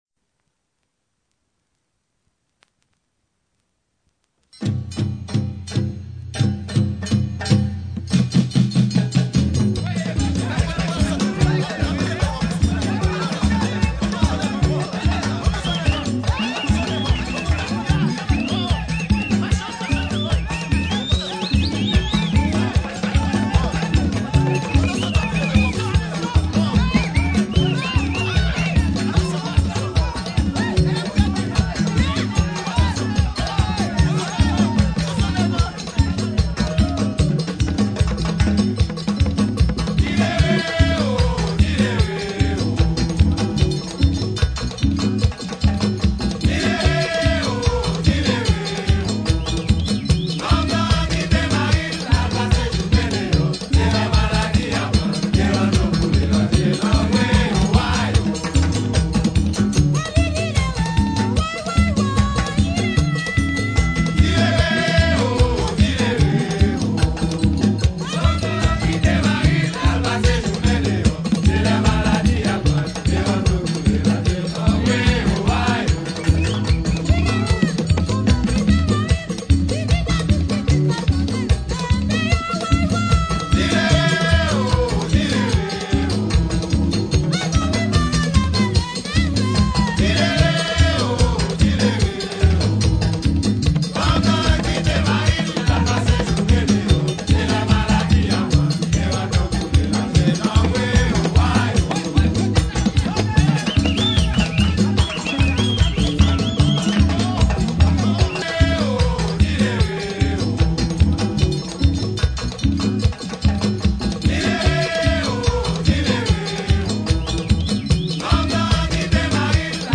MIix rara d’haiti
2022 RARA D'HAITI (VACCINE, FLOKLORE HAITIEN) audio closed https
MIix-rara-dhaiti.mp3